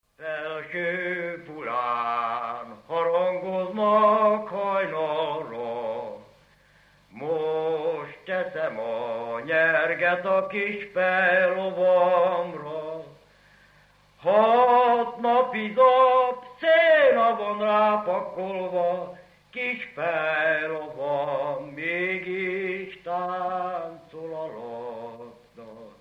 Dunántúl - Sopron vm. - Felsőpulya
ének
Stílus: 5. Rákóczi dallamkör és fríg környezete